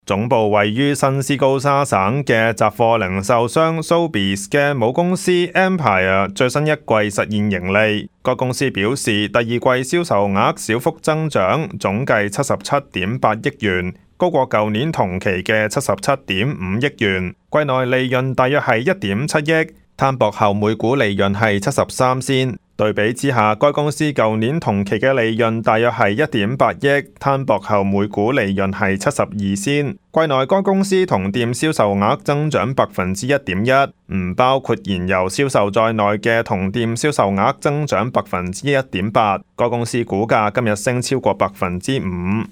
news_clip_21696.mp3